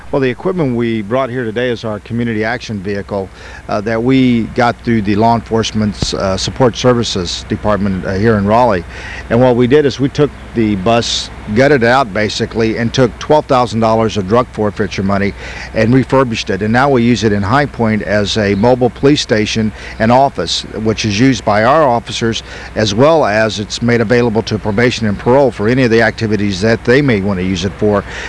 Lou Quijas
Police Chief